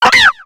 Cri de Furaiglon dans Pokémon X et Y.